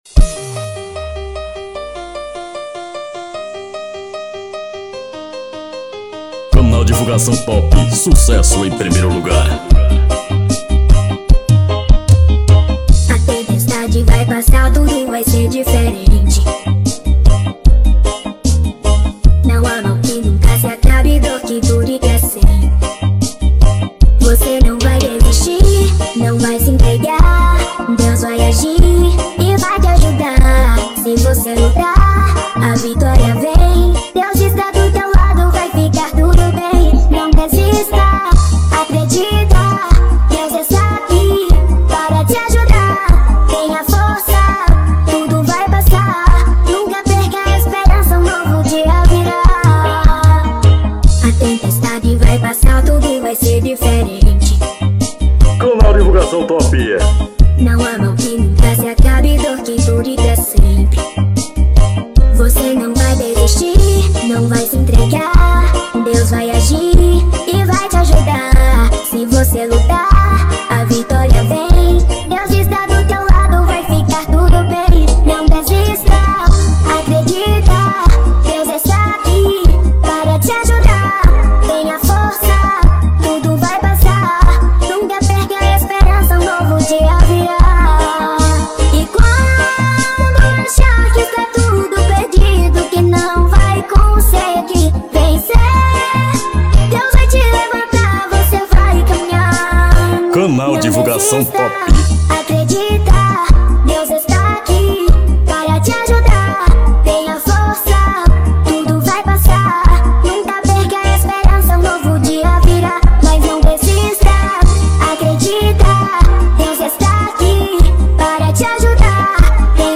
Remix Reggae Gospel